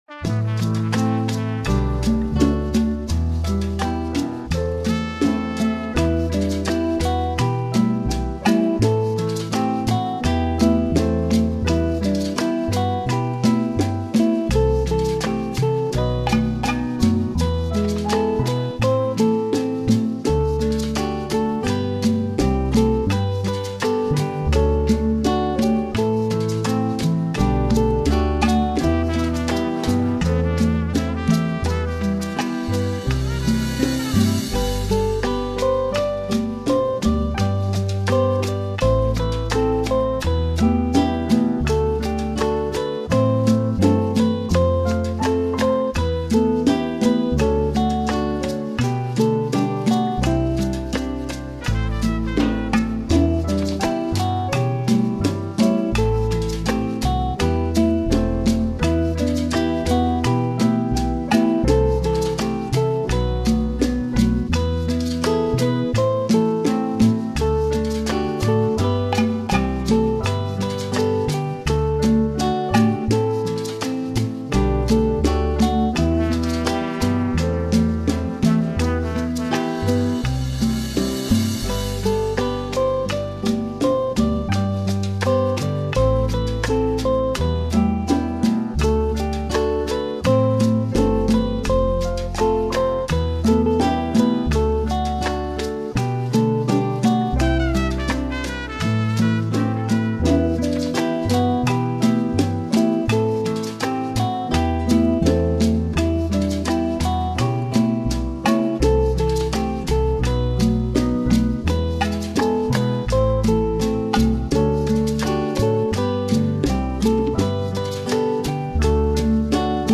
bilingual Spanish-English Communion song